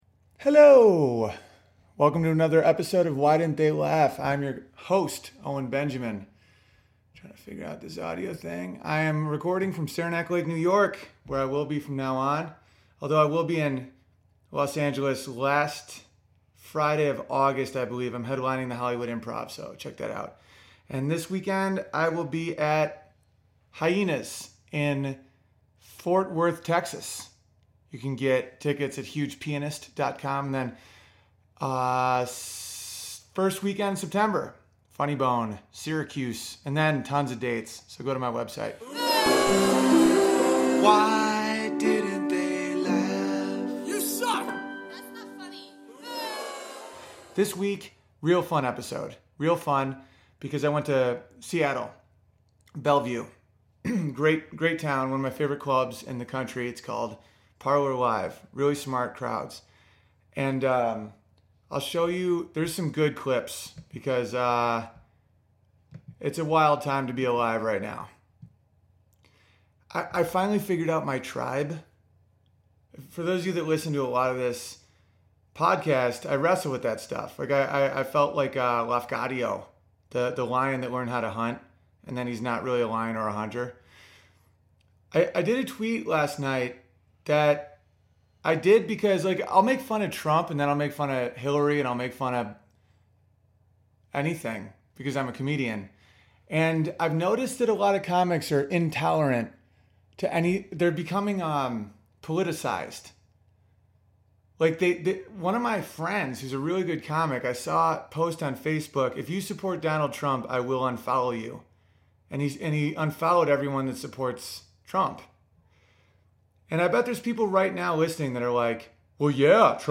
I had a blast at Parlor Live and got some great audio from it.